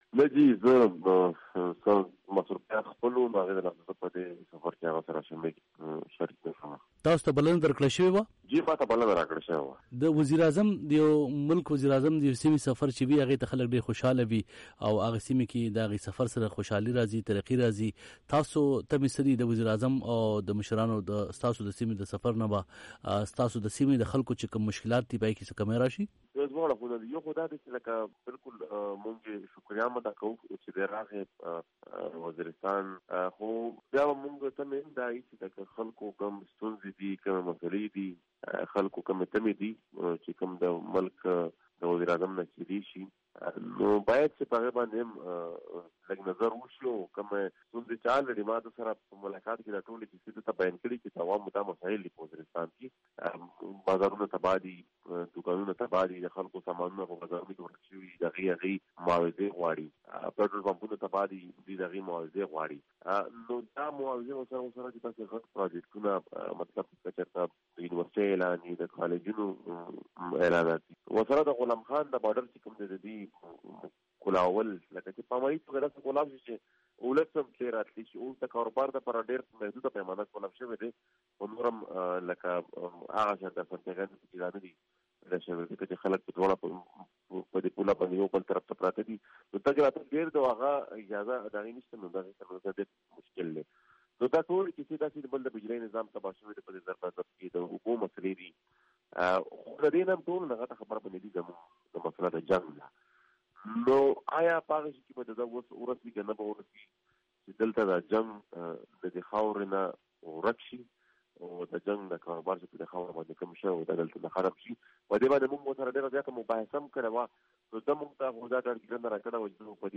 علي وزير او محسن داوړ وي او ای ډیوه ته په ځان ځانله مرکو کې ويلي دي چې وزیراعظم عمران خان ته د سيمې د سفر بلنه دويي ورکړې وه خو چې کله وزیراعظم سيمې ته تللو نو د دويي سره یې صلاح نه وه کړې.